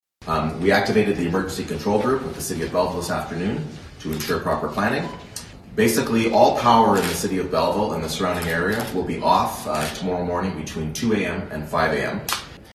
Belleville Mayor Mitch Panciuk elaborated at a press conference at City Hall Friday afternoon.